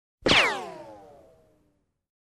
Tiếng Viên Đạn bay
Đánh nhau, vũ khí 310 lượt xem 04/03/2026
Download hiệu ứng âm thanh tiếng viên đạn bay qua Piuuuu… với tốc độ rất cao, rất nhanh chân thực nhất edit video.